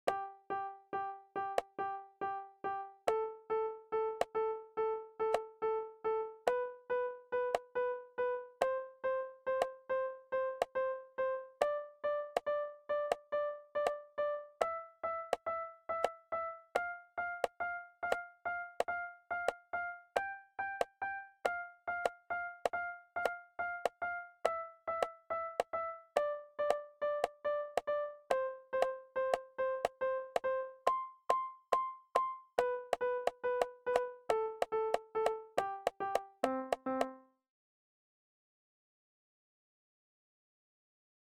435. Beat Accel fixed